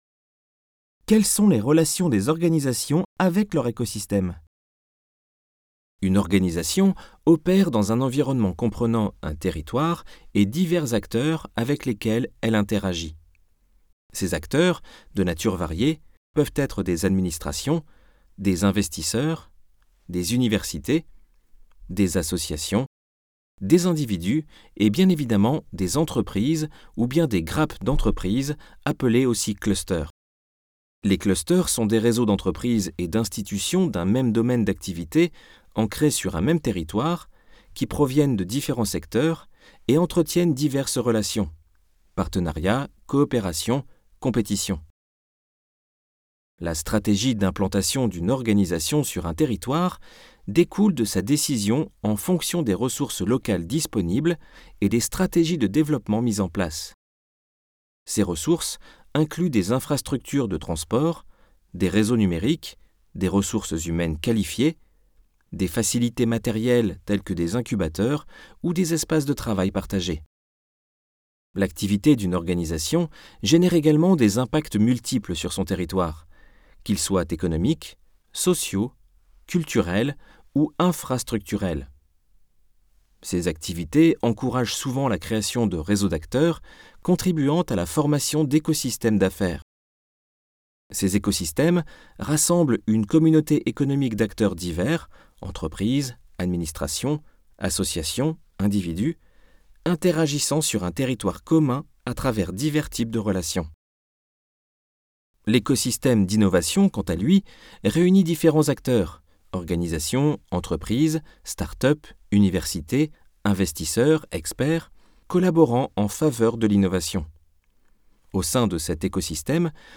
Synthèse audio du chapitre